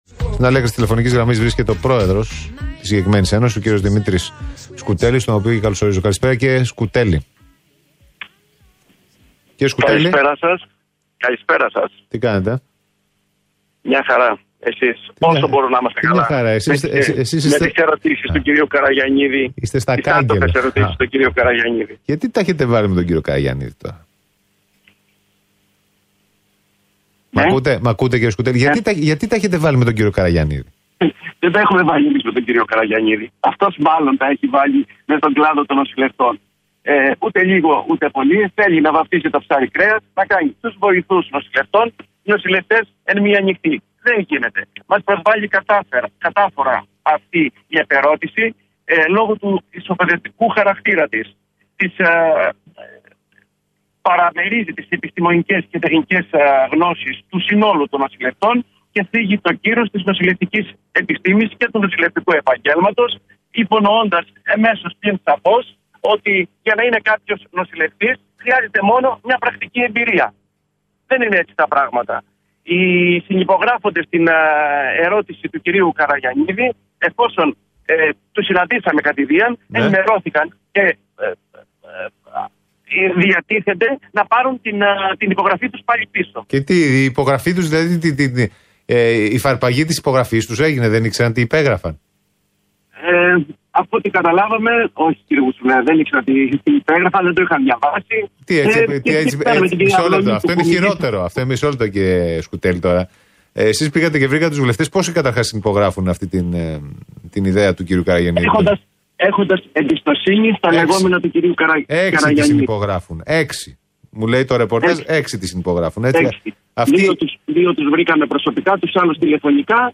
μιλώντας το μεσημέρι στην ραδιοφωνική εκπομπή του Alpha 98.9